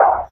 nether_footstep.2.ogg